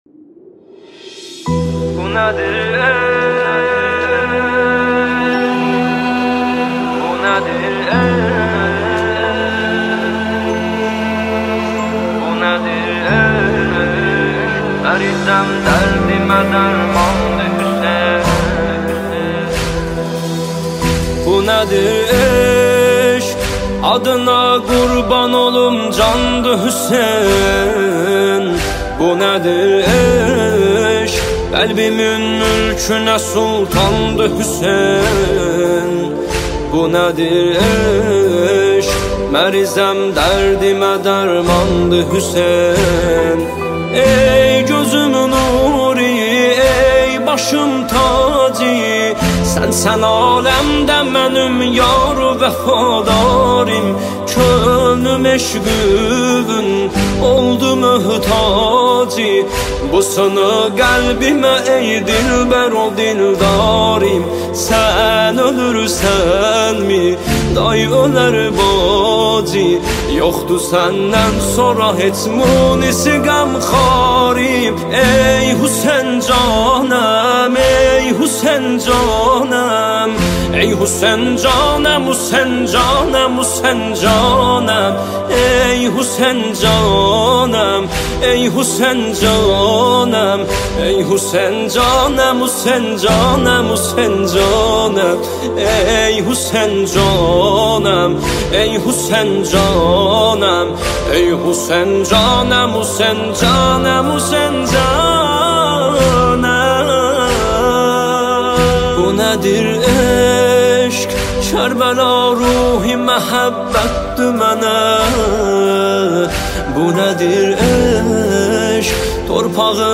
دانلود نماهنگ دلنشین ترکی